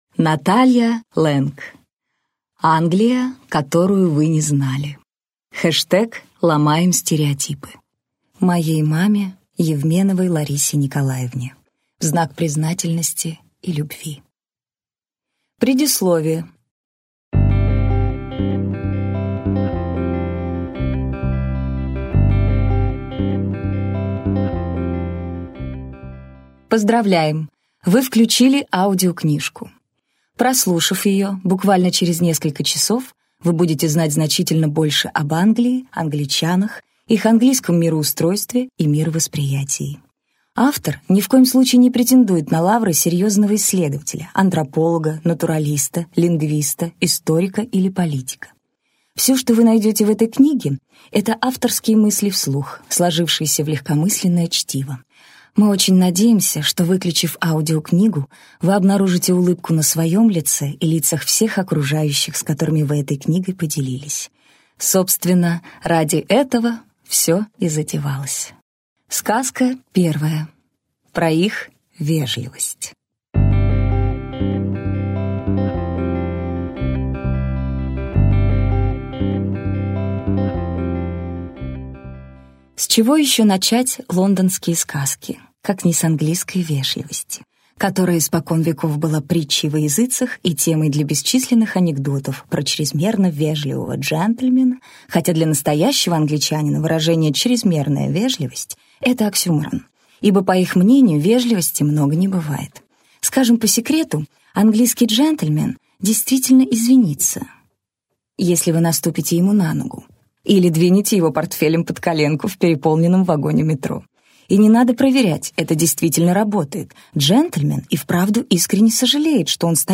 Аудиокнига Англия, которую вы не знали | Библиотека аудиокниг